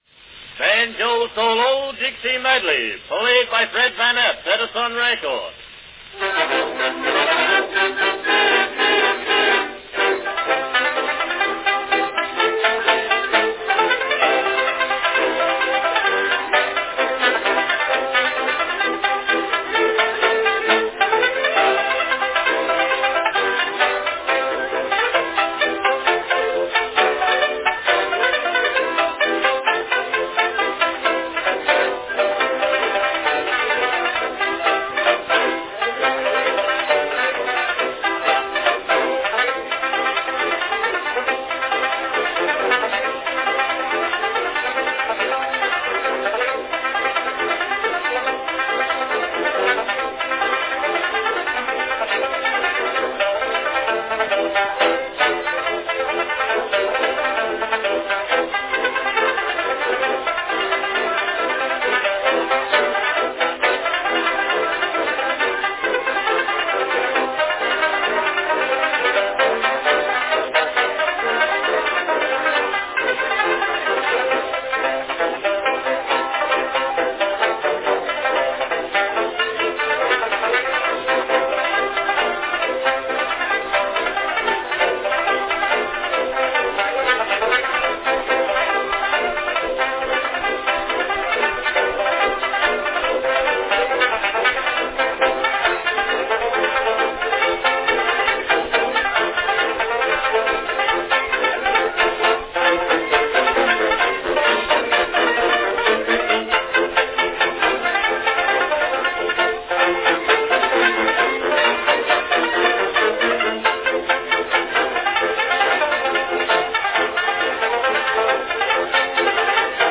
Category Banjo solo
A great banjo solo with vibrant tuba accompaniment.
This particular recording is apparently a re-make of the original 1903 release.